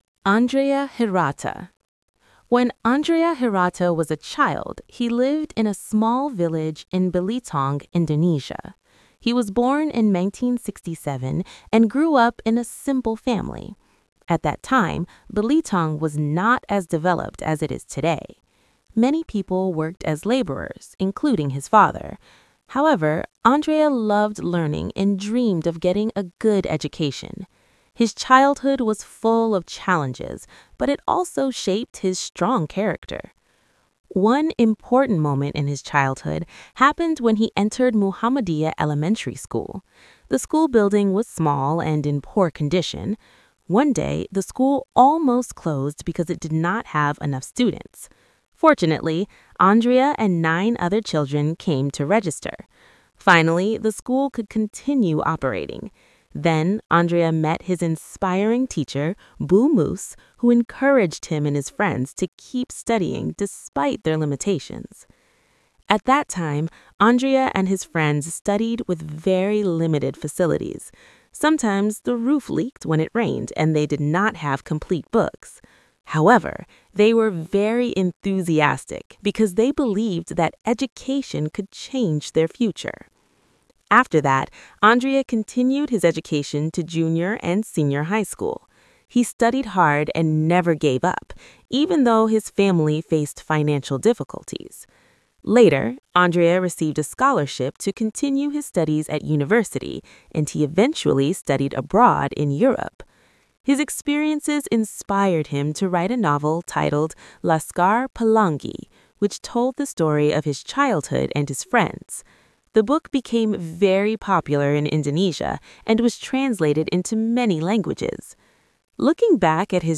Listening of Recount Text: Andrea Hirata